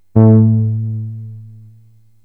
SYNTH BASS-2 0015.wav